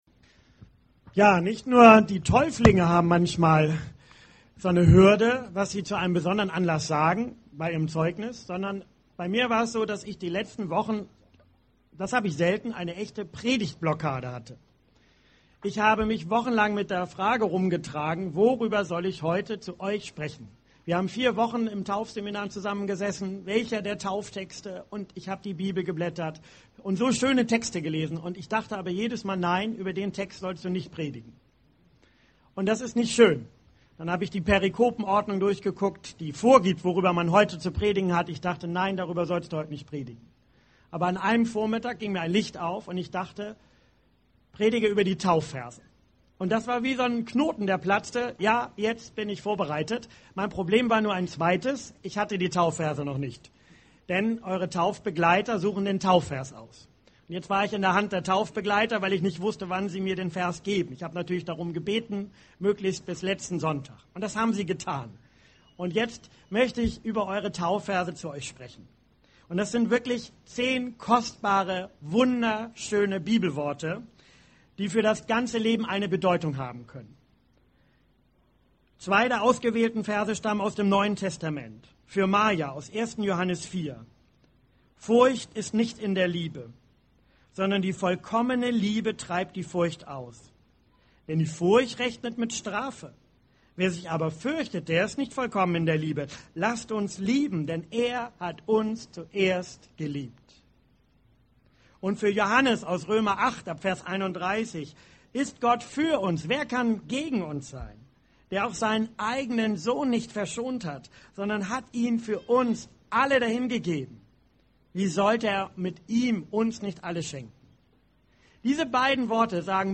Taufpredigt: Taufverse der Täuflinge